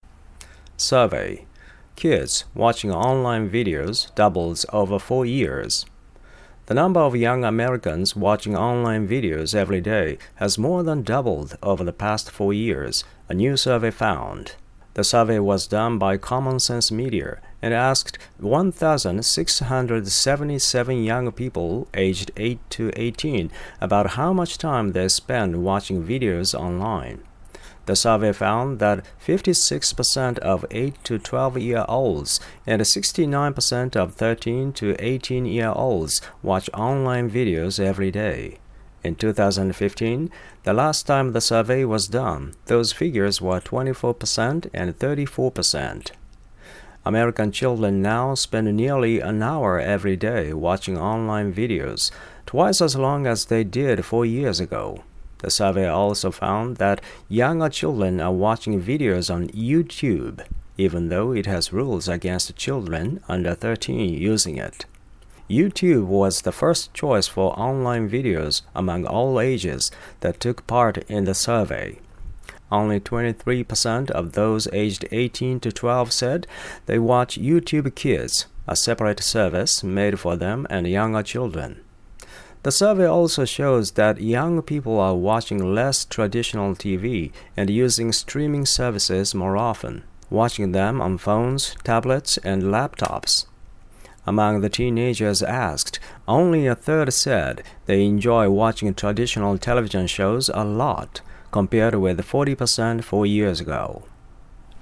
急遽、ひとつ読んでみました